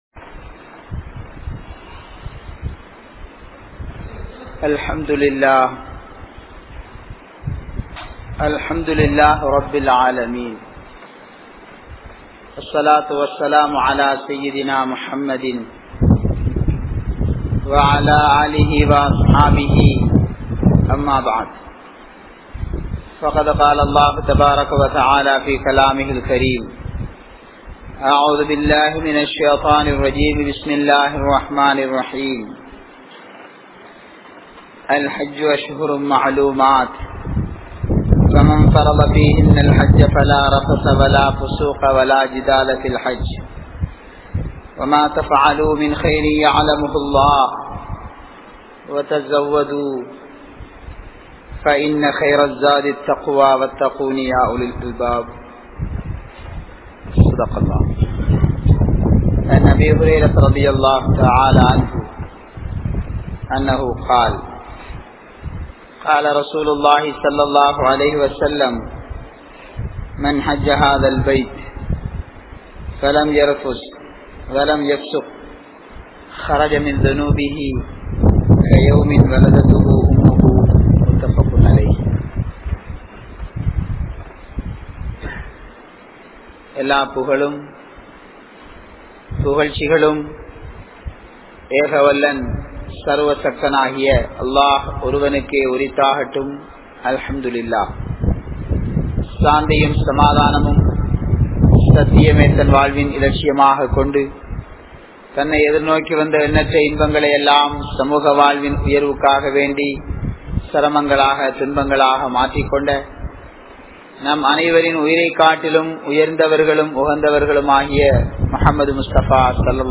Hajjin Noakkam (ஹஜ்ஜின் நோக்கம்) | Audio Bayans | All Ceylon Muslim Youth Community | Addalaichenai
Kanampittya Masjithun Noor Jumua Masjith